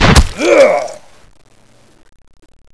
corpse_attack1.wav